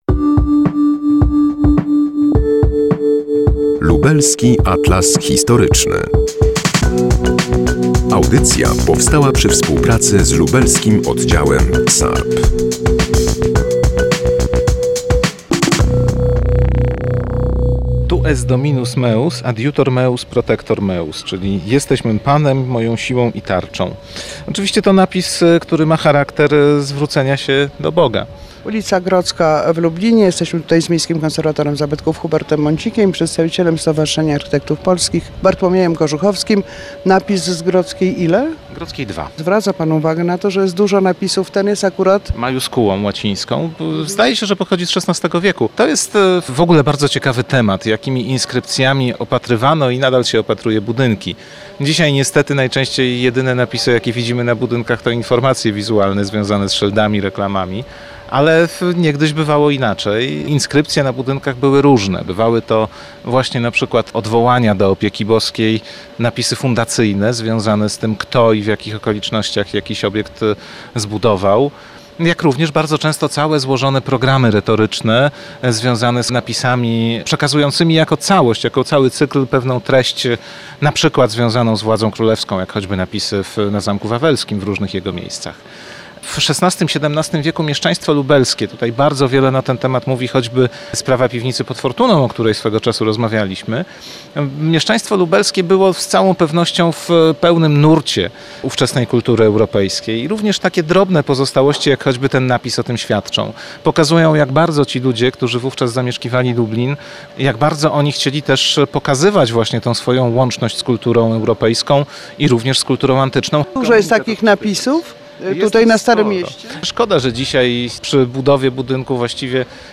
Na ul. Grodzkiej w Lublinie jesteśmy